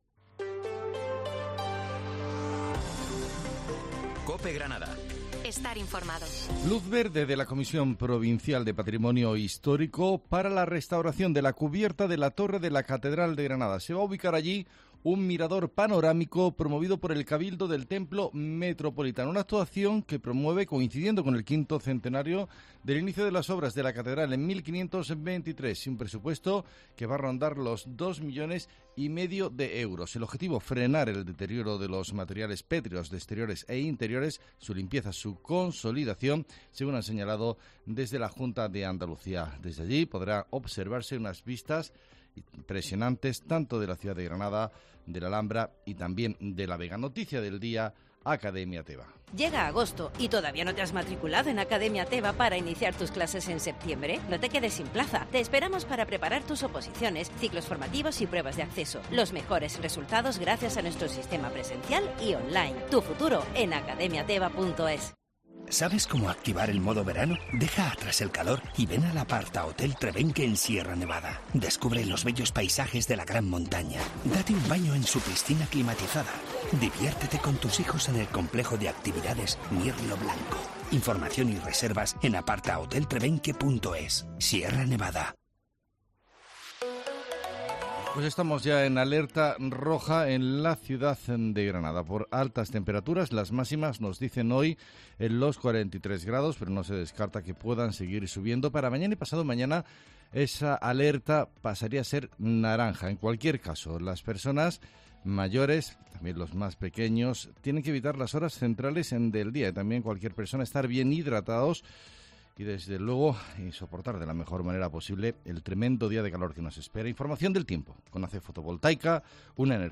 Herrera en Cope Granada. Informativo del 9 de agosto